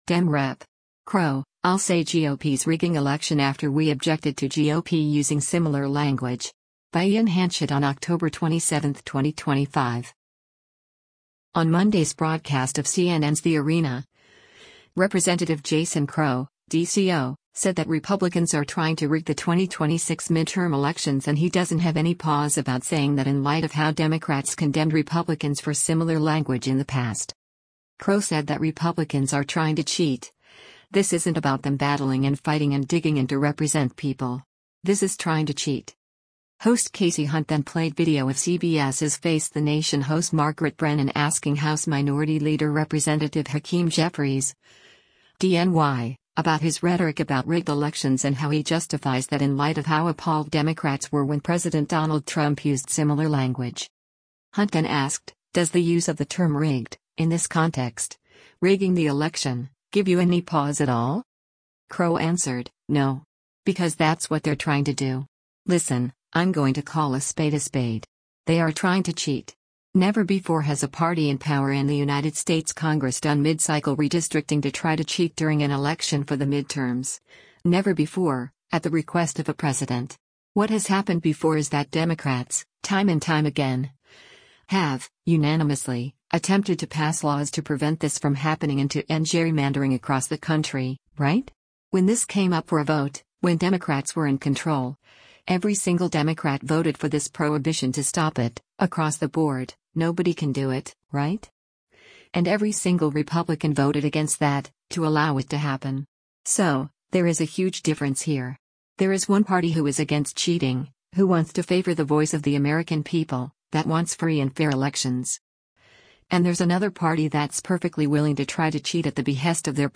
On Monday’s broadcast of CNN’s “The Arena,” Rep. Jason Crow (D-CO) said that Republicans are trying to rig the 2026 midterm elections and he doesn’t have any pause about saying that in light of how Democrats condemned Republicans for similar language in the past.
Host Kasie Hunt then played video of CBS’s “Face the Nation” host Margaret Brennan asking House Minority Leader Rep. Hakeem Jeffries (D-NY) about his rhetoric about “rigged elections” and how he justifies that in light of how “appalled” Democrats were when President Donald Trump used similar language.